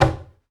Table_Wood_Hit_Simple_Dull.wav